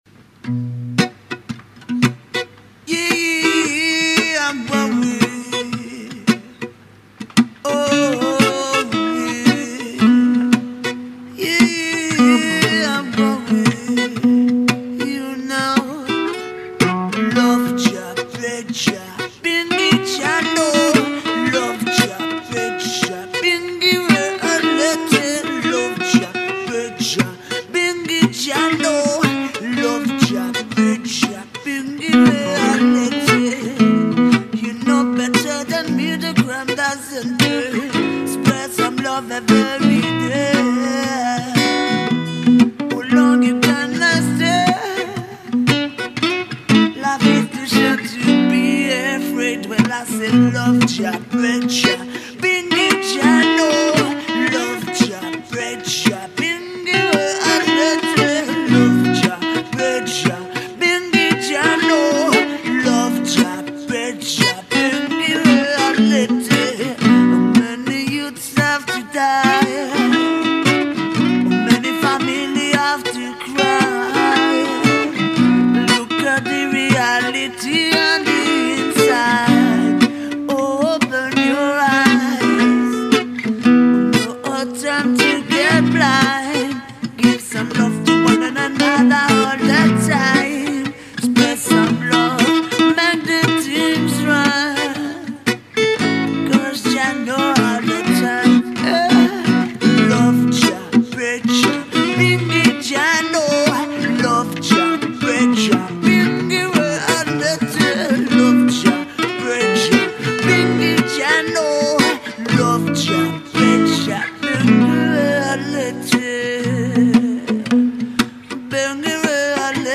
morning freestyle acoustique